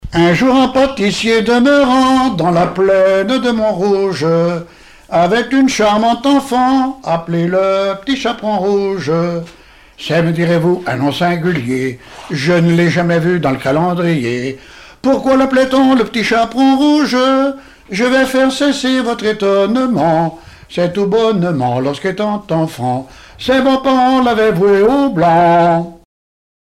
Contes et légendes chantés
Témoignages et chansons
Pièce musicale inédite